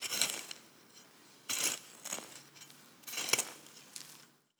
SFX_Harke_02_Reverb.wav